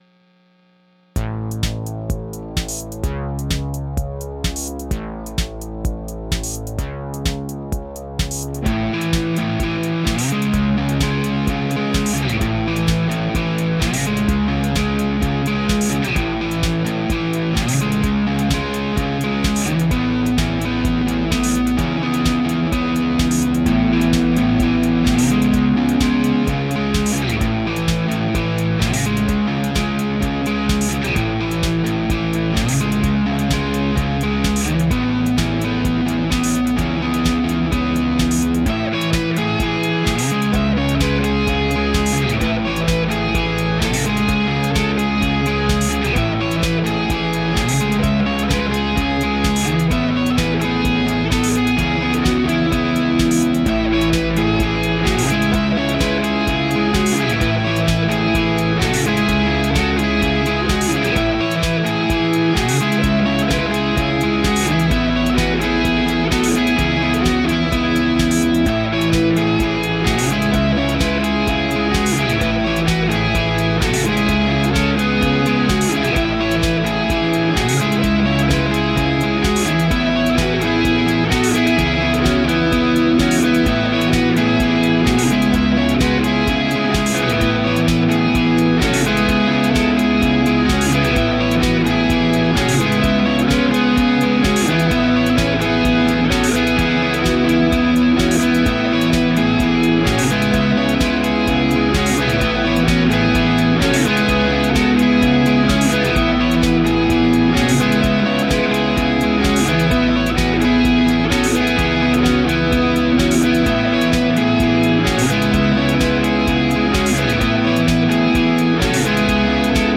I learned how to use the Deluge as a looper! This is a quick jam just adding some layers to a bass/drums foundation.